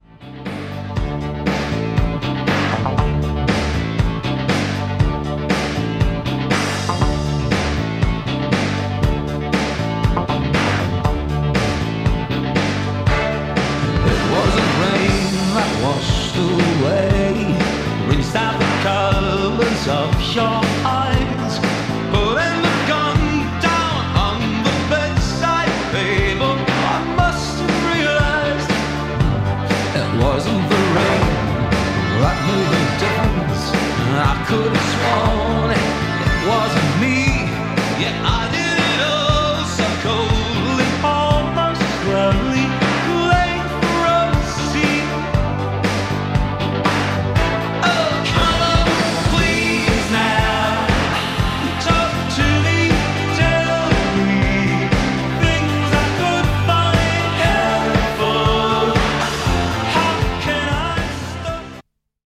It is an old school front loader with a really heavy mechanism that produces decently low wow & flutter results.
Here is a test recording made and played back on the RT-2050